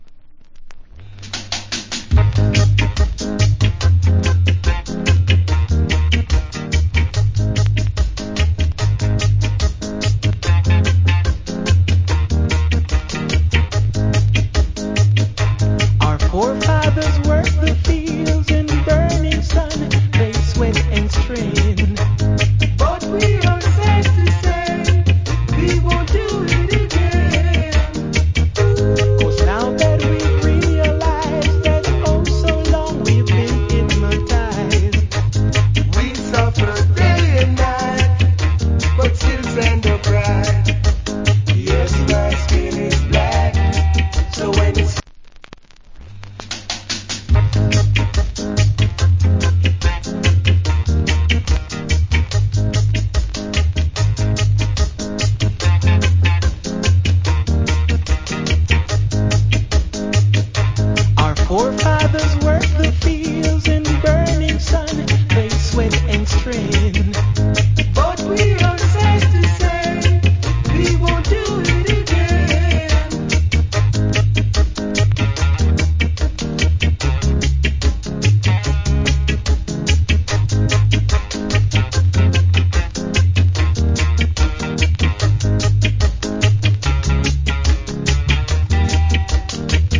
Old Hits Early Reggae Vocal.